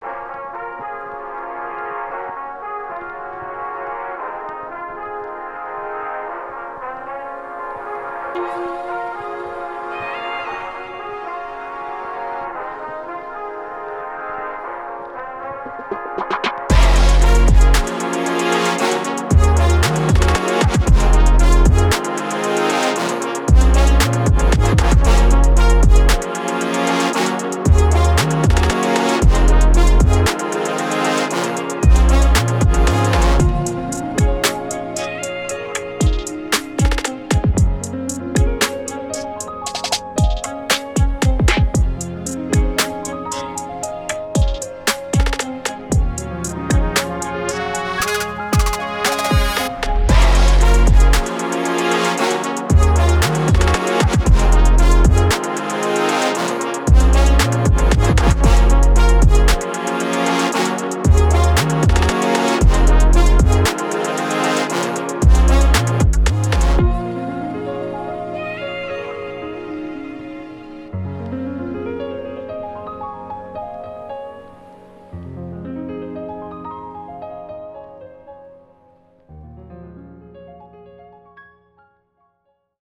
(Hip Hop)